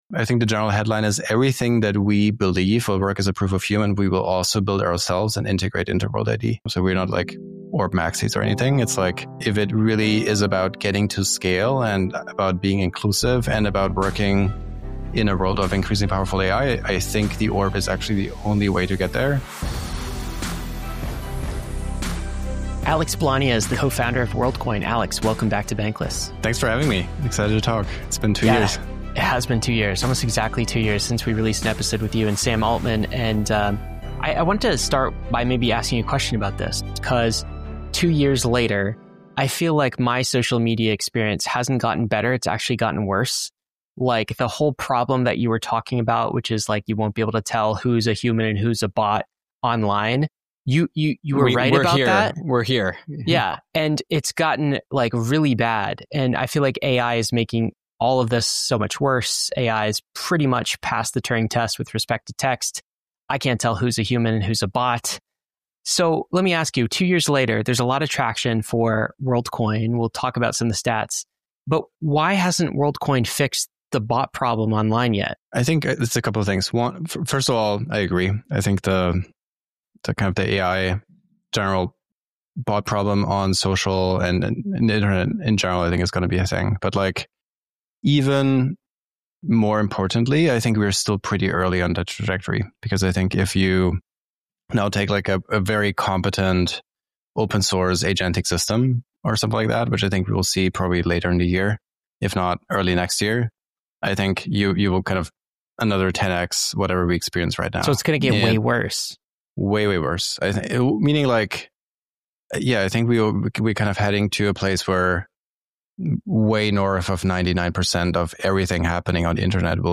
Whether you're curious about AI-proof identity or sceptical of eyeball scanning orbs, this conversation cuts deep.